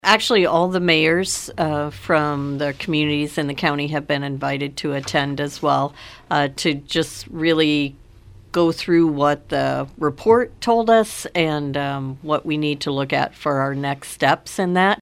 That is County Supervisor Carol Hibbs who was part of the EMS Task Force.